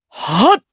Lucias-Vox_Attack4_jp_b.wav